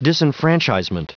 Prononciation du mot disenfranchisement en anglais (fichier audio)
Prononciation du mot : disenfranchisement